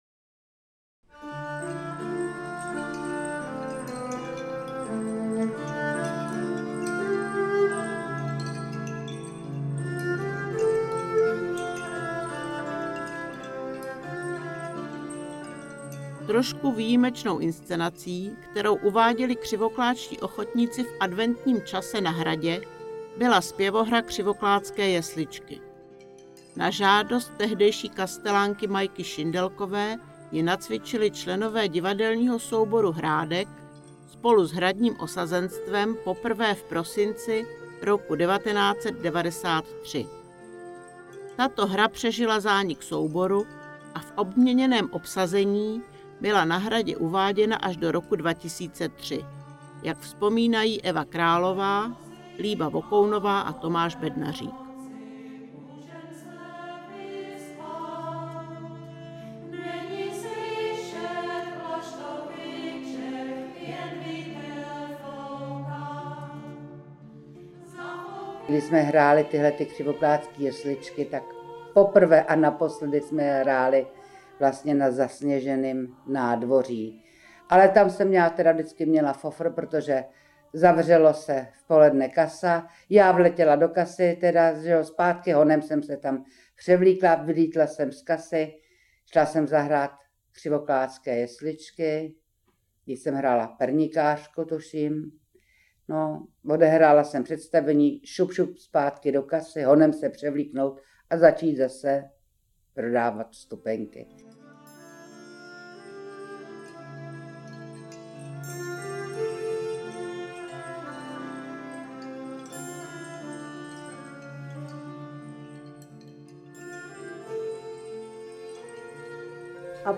Ve stopách Thalie, Vzpomínky - bonus je audio přílohou dvoudílného filmového průvodce po historii ochotnického divadla v městysi Křivoklát. Komentované listinné a obrazové dokumenty, vzpomínky křivoklátských ochotníků.